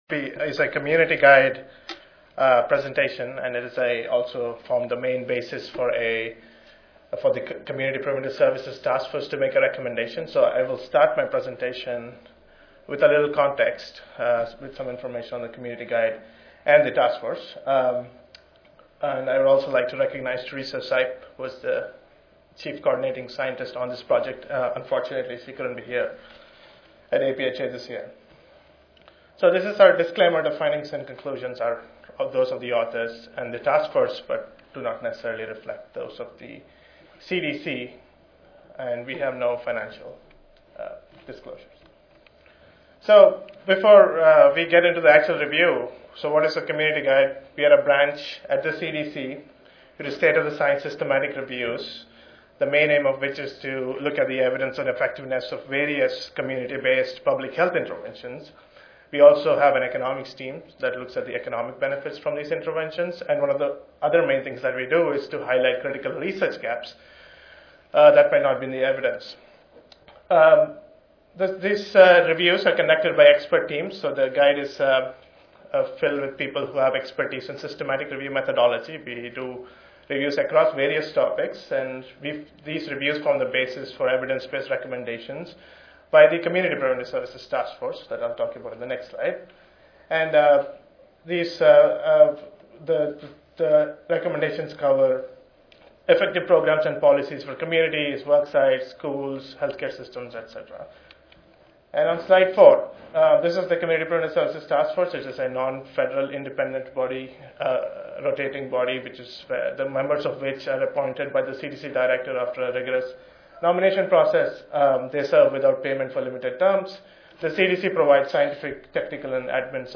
4394.0 Primary Care and the Chronic Care Model Tuesday, November 1, 2011: 4:30 PM Oral Chronic Disease Management led by teams is one of the ways to achieve outcomes at or better than national benchmarks at a fraction of the cost.